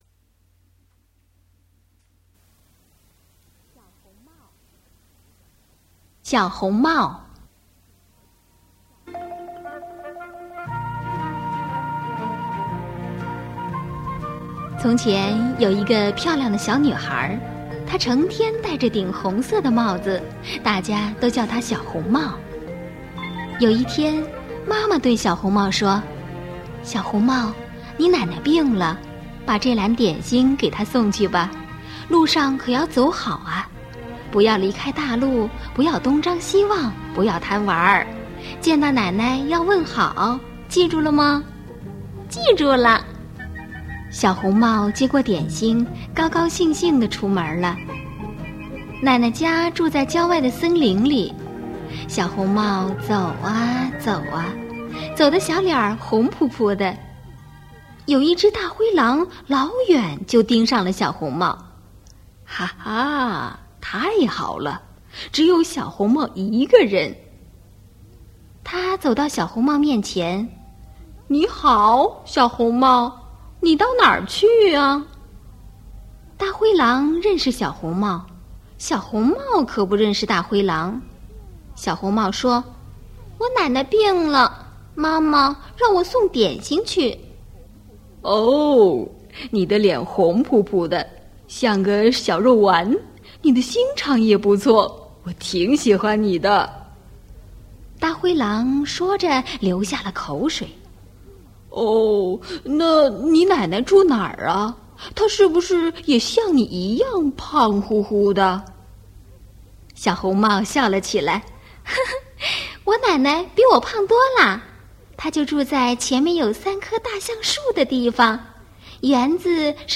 Audiobook «Little Red Riding Hood» in Chinese (小红帽)